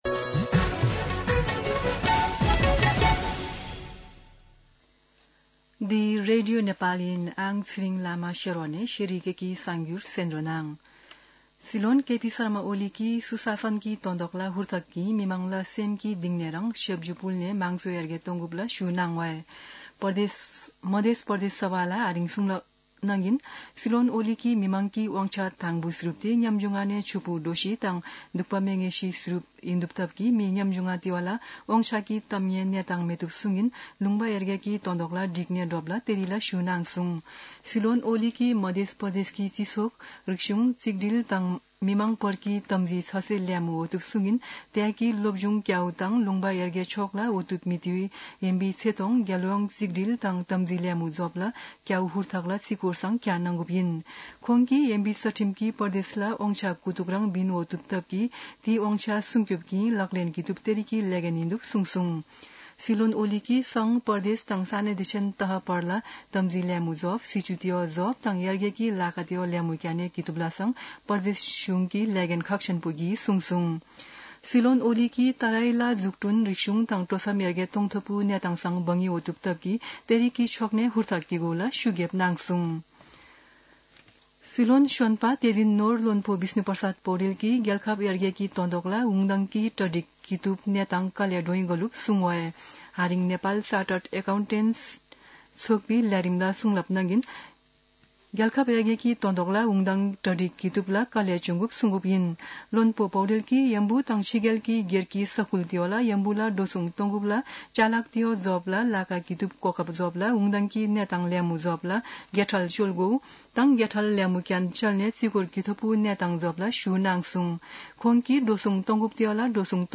शेर्पा भाषाको समाचार : २६ चैत , २०८१
Sherpa-News-26.mp3